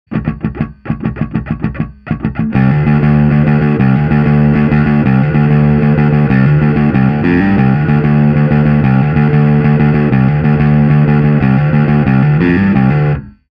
An extremely versatile, warm sounding transparent overdrive.
Smog on BASS
Amp: Ampeg SVT-CL
Cab: Ampeg SVT-810E
Bass: Fender Jazz ‘66